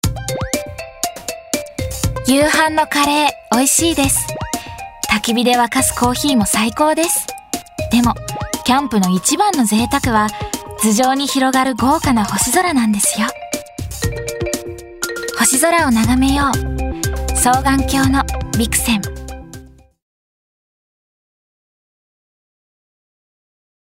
ラジオCM